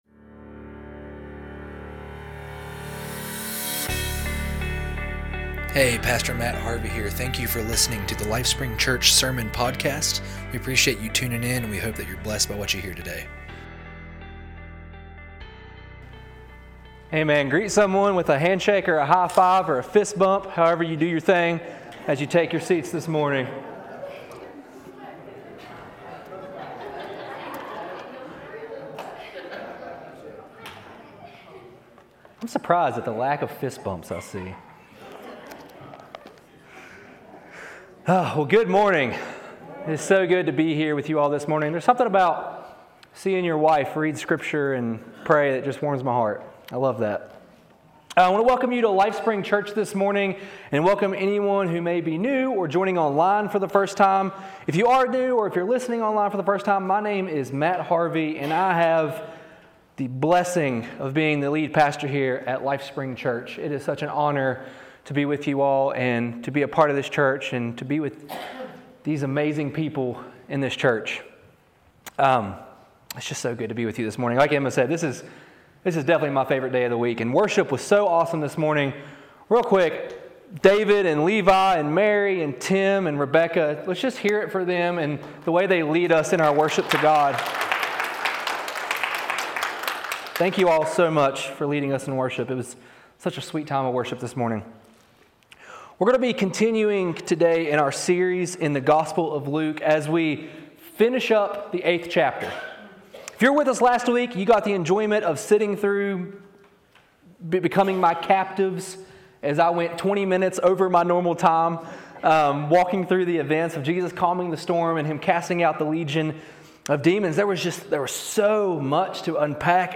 Sermons | LifeSpring Church